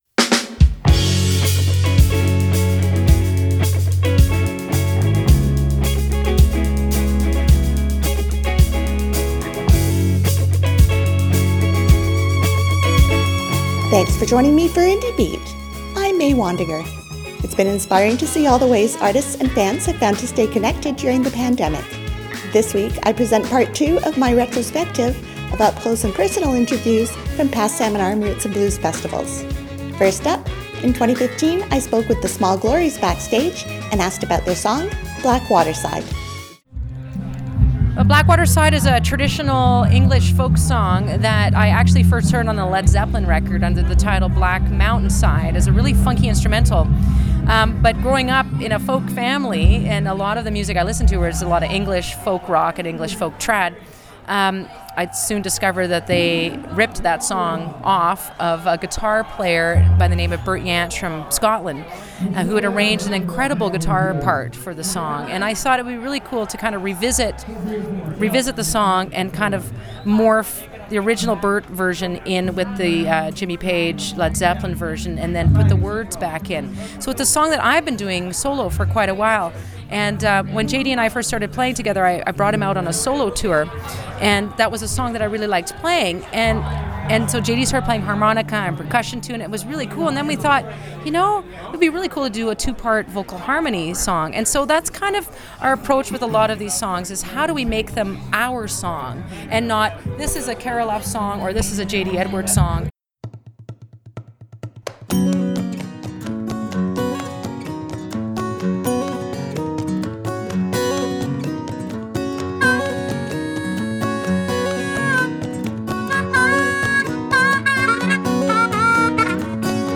Interviews and music from several artists I've spoken with at past festivals